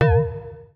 Universal UI SFX / Clicks
UIClick_Marimba Metal Wobble 03.wav